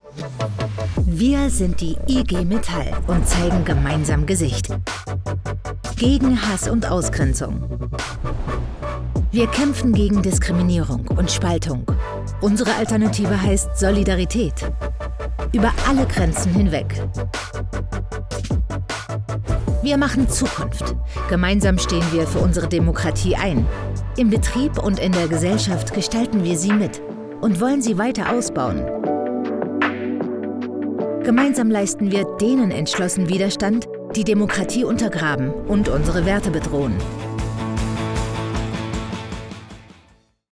Meine Stimme ist warm, klar und vielseitig einsetzbar – ob für Werbung, Imagefilme, E‑Learnings, Audioguides oder Dokumentationen.
Kund:innen schätzen meinen natürlichen, empathischen Ton, die präzise Aussprache und eine zuverlässige, unkomplizierte Zusammenarbeit.
Broadcast‑ready Aufnahmen entstehen in meinem eigenen Studio in Berlin.
Sprechprobe: Industrie (Muttersprache):